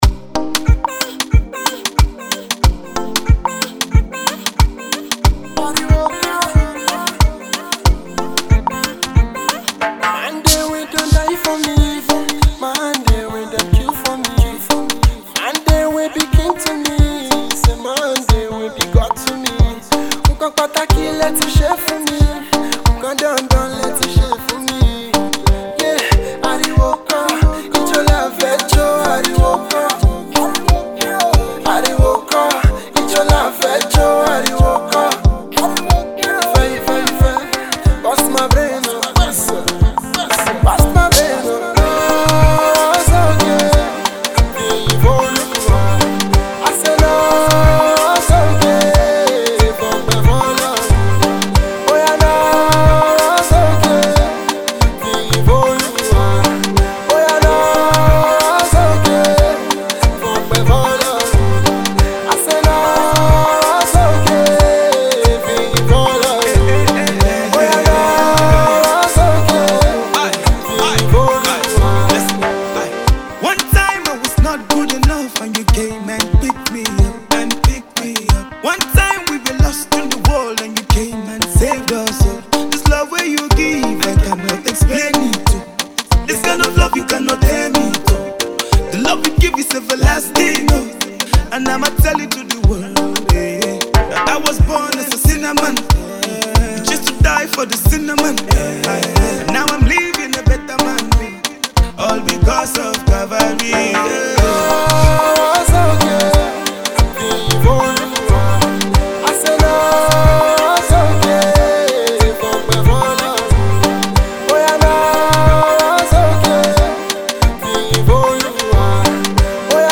an Afropop tune